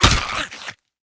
PixelPerfectionCE/assets/minecraft/sounds/mob/stray/hurt1.ogg at mc116